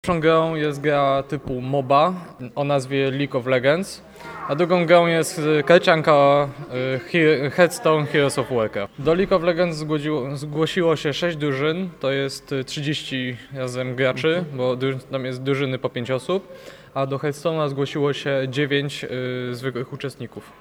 W “Łączniku” zgromadzili się zwolennicy, o których liczebności opowiedział nam jeden z organizatorów: